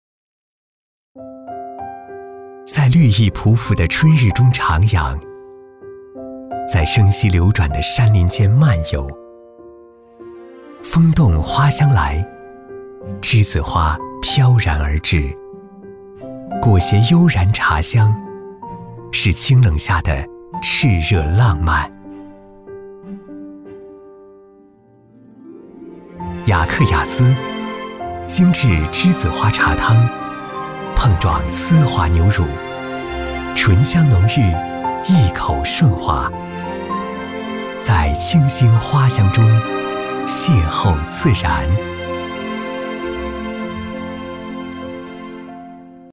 B男71号
【广告】年轻感性广告 雅克雅思
【广告】年轻感性广告 雅克雅思 小样.mp3